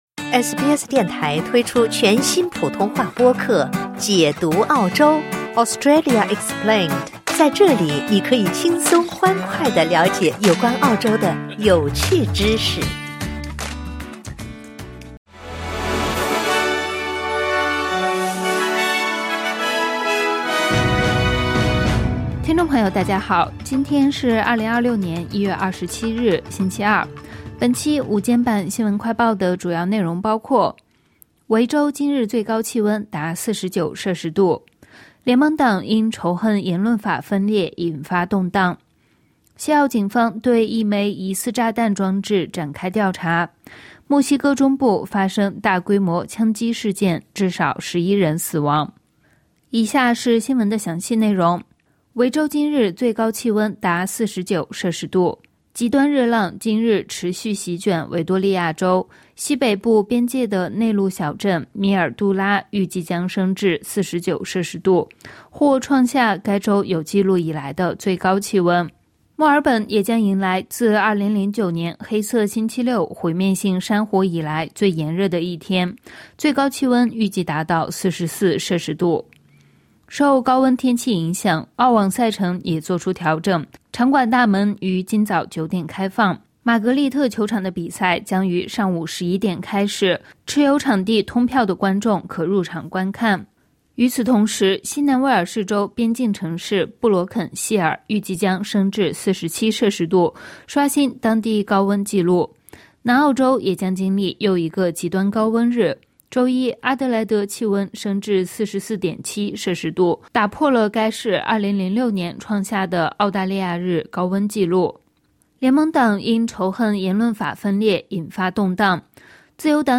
【SBS新闻快报】维州今日最高气温或达49摄氏度 澳网赛程调整